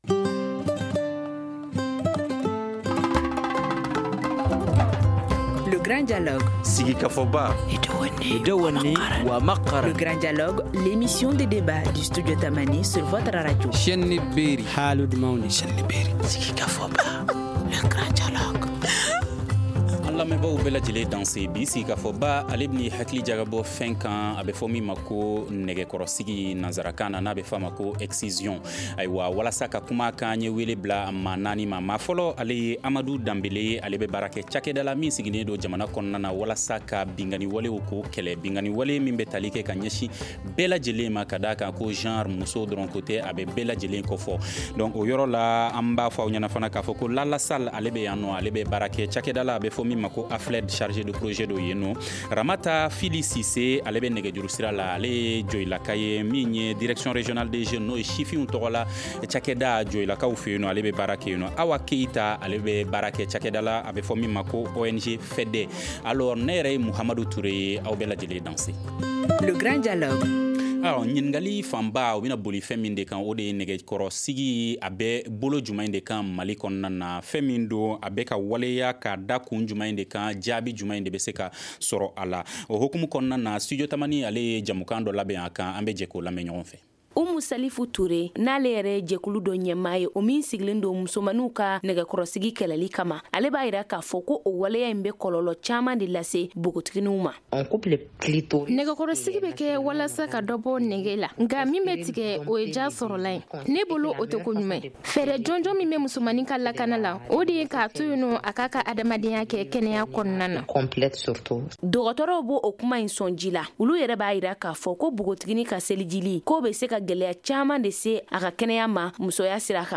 Le Grand de Studio Tamani pose le débat dans le cadre de la journée mondiale tolérance zéro des mutilations génitales féminines, célébrée ce lundi 06 février.